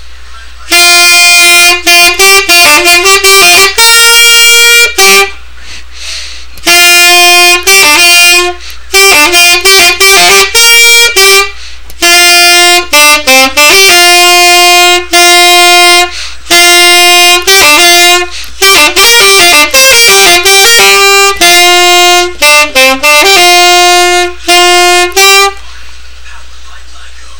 My solo for marching band
This is a little thing I play during the marching show, which I think was REALLY hard to start playing on the tenor sax. Since it is so high and fast.